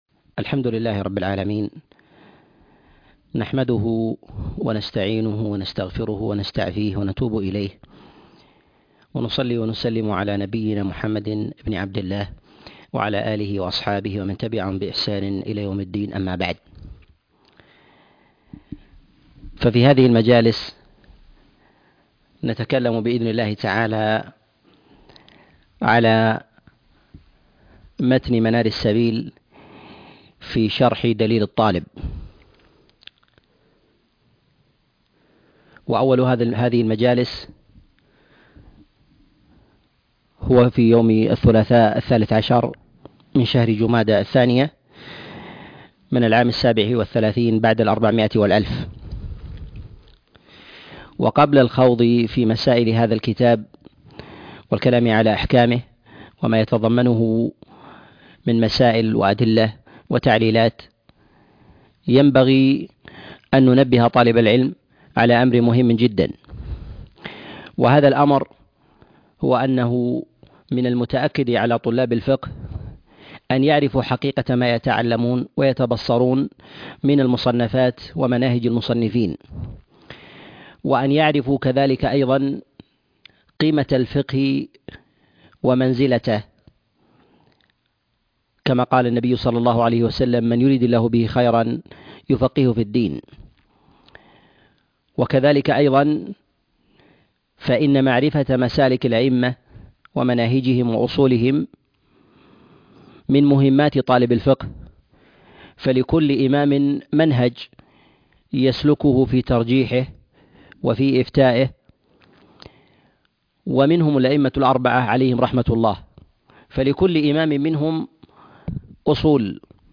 شرح منار السبيل -الفقه الحنبلي الدرس 1